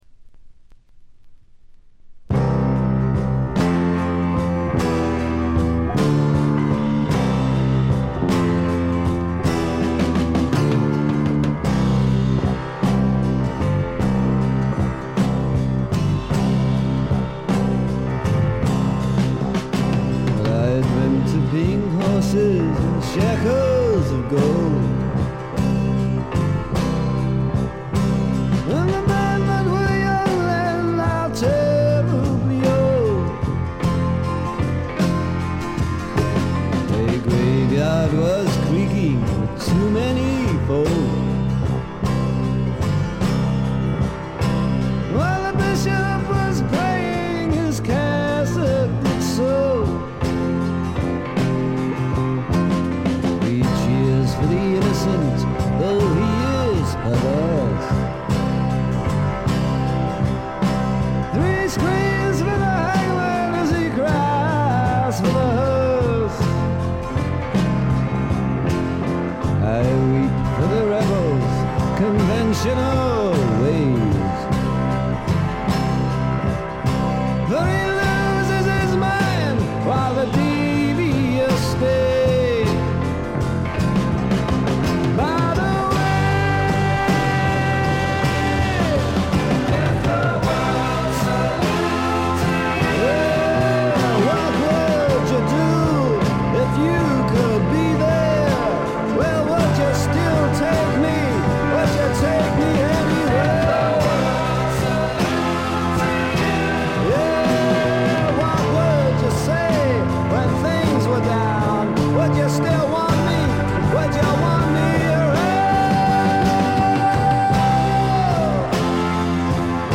部分試聴ですが、微細なノイズ感のみ、極めて良好に鑑賞できると思います。
試聴曲は現品からの取り込み音源です。